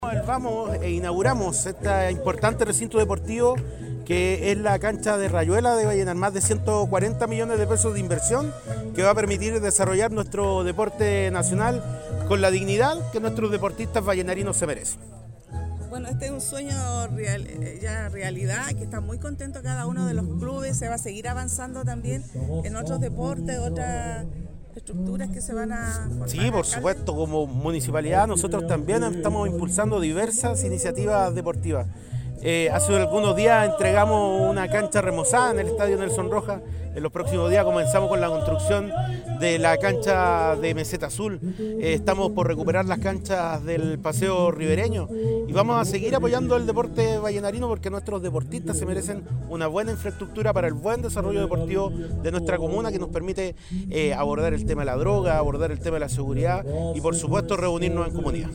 Por su parte, el alcalde de Vallenar, Víctor Isla, valoró la concreción de un anhelo histórico de los clubes rayueleros de la comuna.